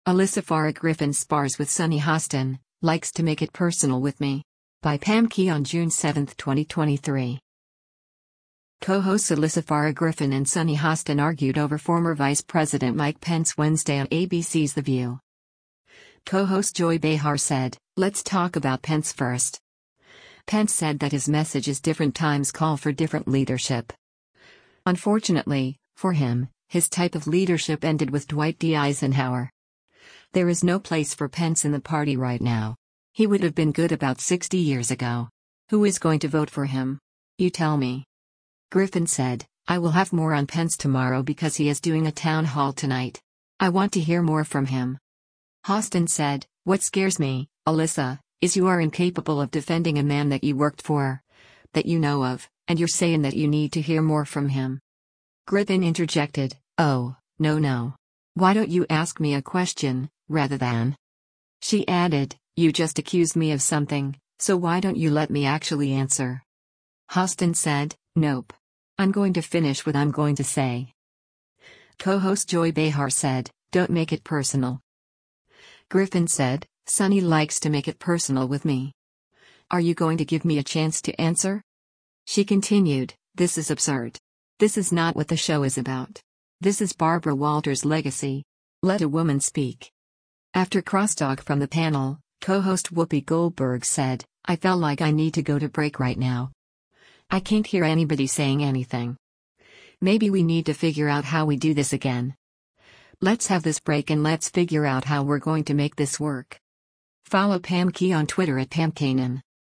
Co-hosts Alyssa Farah Griffin and Sunny Hostin argued over former Vice President Mike Pence Wednesday on ABC’s “The View.”
After crosstalk from the panel, co-host Whoopi Goldberg said, “I fell like I need to go to break right now.